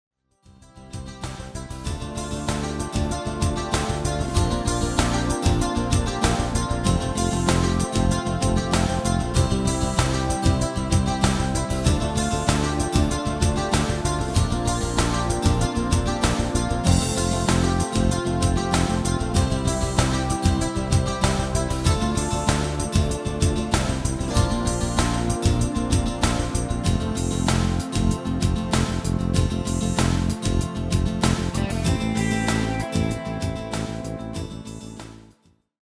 Demo/Koop midifile
Genre: Pop & Rock Internationaal
- Géén tekst
- Géén vocal harmony tracks